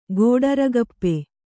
♪ gōḍaragappe